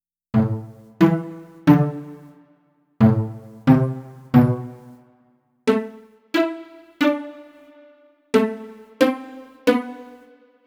Pizz String.wav